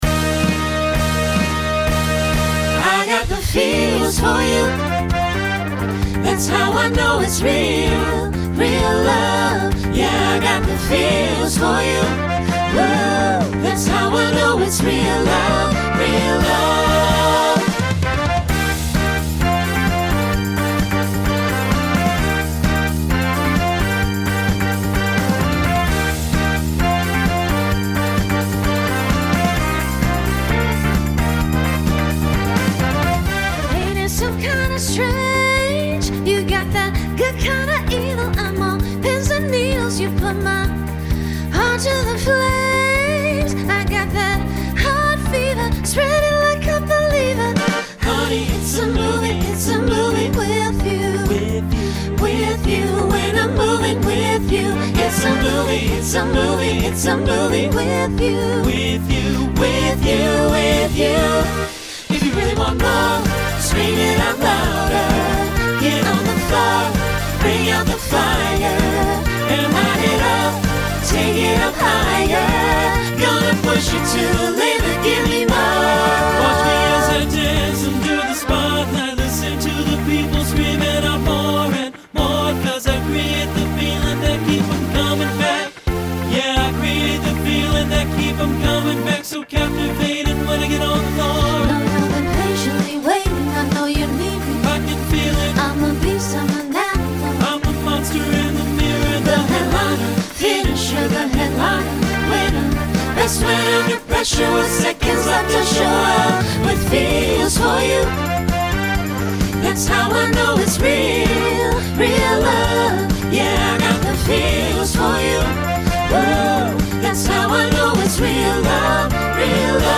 Genre Pop/Dance Instrumental combo
Voicing SATB